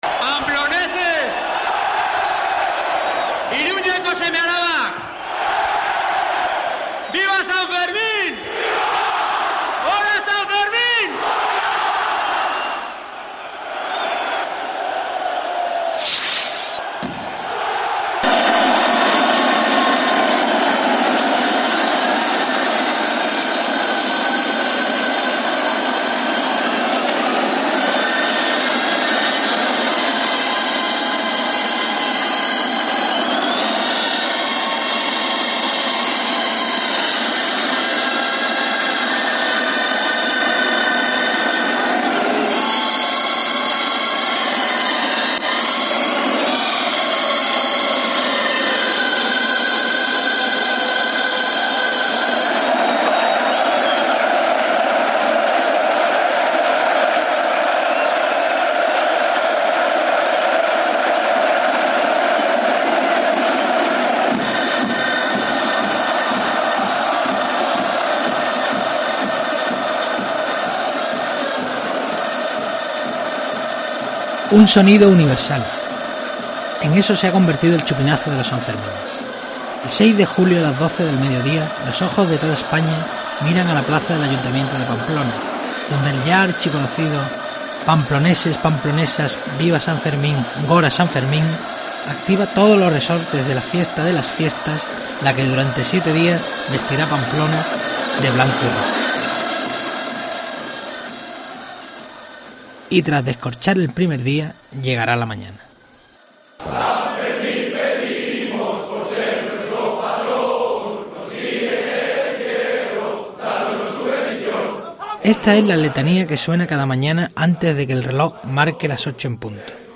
Un sonido universal.
Esta es la letanía que suena cada mañana antes de que el reloj marque las 8 en punto.
Las charangas son las que tocan diana todas las mañanas por el centro de Pamplona después del encierro, porque lo de las 24 de horas de fiesta no es un mito.